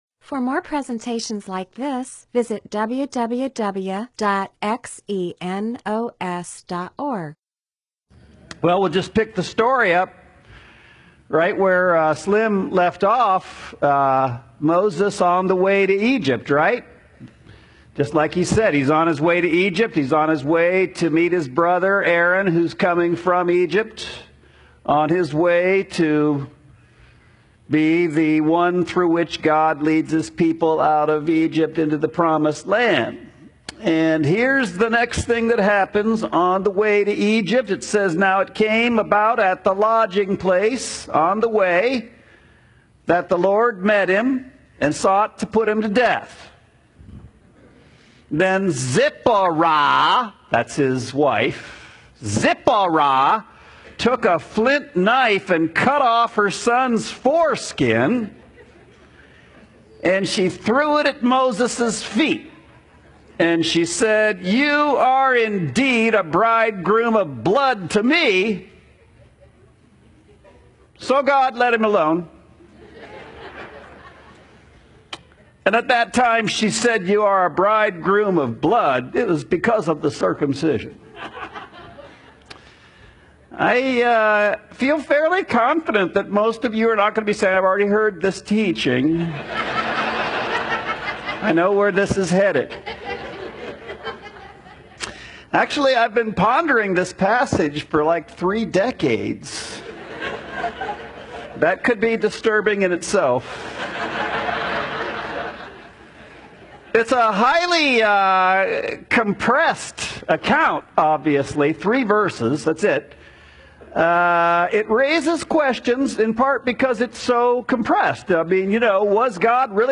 MP4/M4A audio recording of a Bible teaching/sermon/presentation about Exodus 4:24-26.